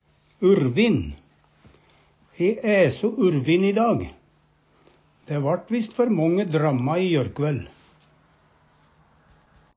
Høyr på uttala Ordklasse: Adjektiv Kategori: Kropp, helse, slekt (mennesket) Attende til søk